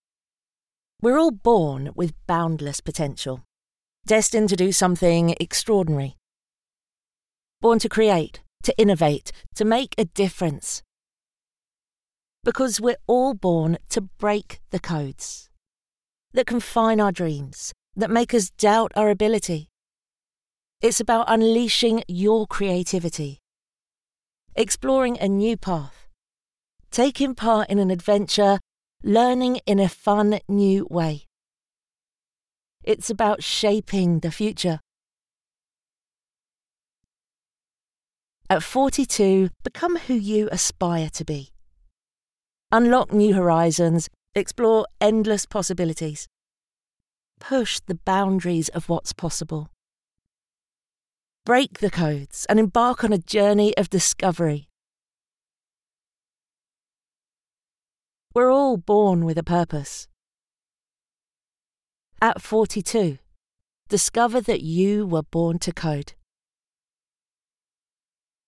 Natural, Llamativo, Accesible, Versátil, Cálida
Explicador